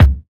• Bumpin' Kick Single Shot C Key 393.wav
Royality free kickdrum sample tuned to the C note. Loudest frequency: 270Hz
bumpin-kick-single-shot-c-key-393-JBo.wav